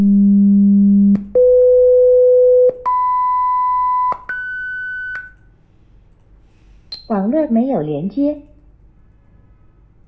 暂停音乐后、当客户测试音乐源或通过"声音"框播放音乐时、将会发出噼啪声。
暂停 POP 声音来自 TAS5754M、因为录制 的"停止-返回"信号中有噼啪声。
pop.wav